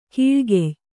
♪ kīḷgey